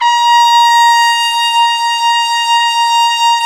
Index of /90_sSampleCDs/Roland L-CDX-03 Disk 2/BRS_Trumpet 1-4/BRS_Tp 3 Ambient